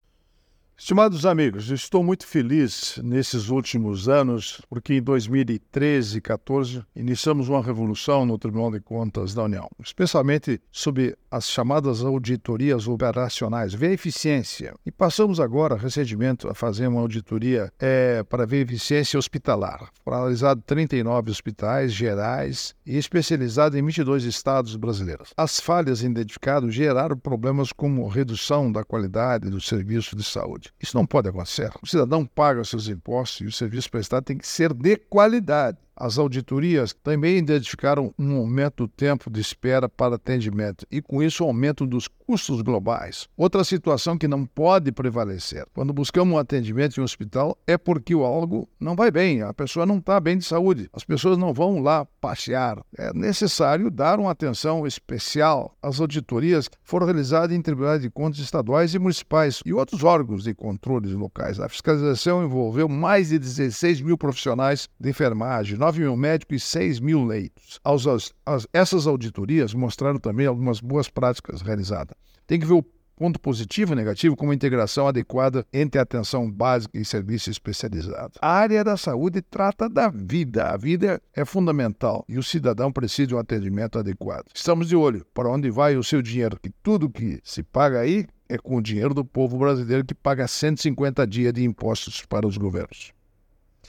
É o assunto do comentário desta sexta-feira (26/07/24) do ministro Augusto Nardes (TCU), especialmente para OgazeteirO.